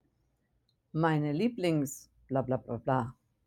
mein/e Lieblings-. (mein/e LIEB-lings)